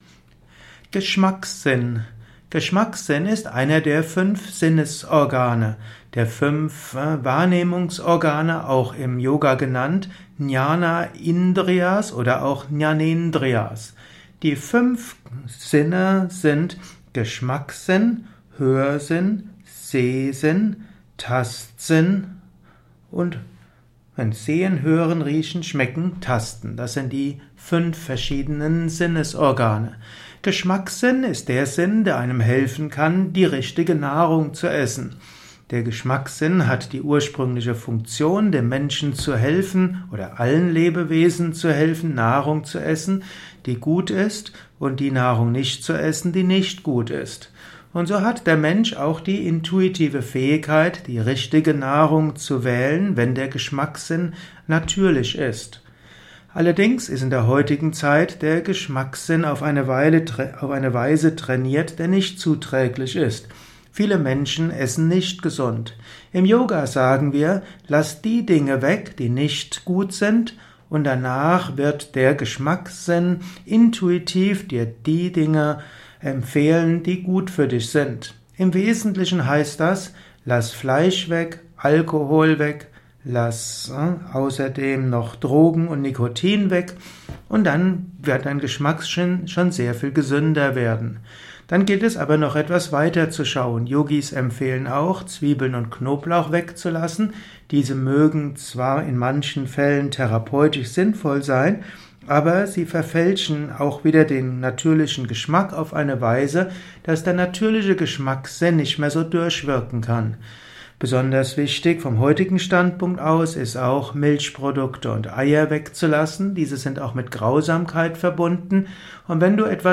Ein Kurzvortrag über den Geschmackssinn